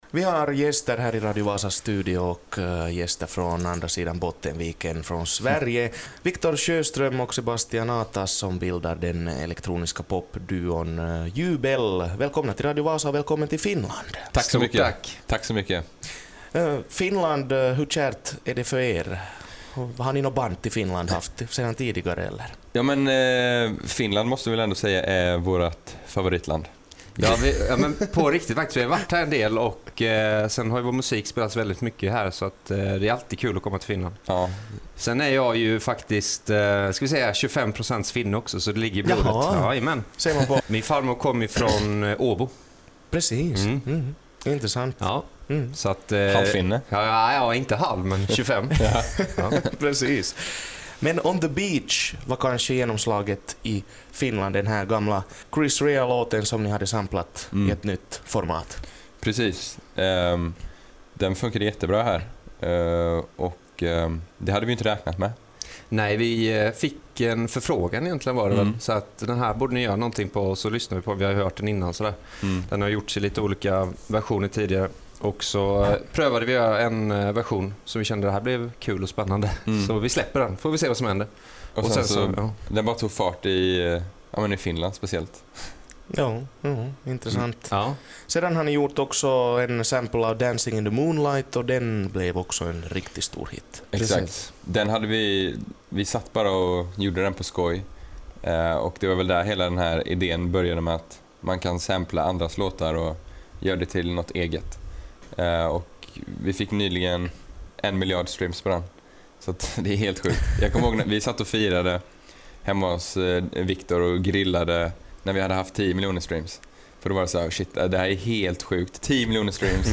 intervjuas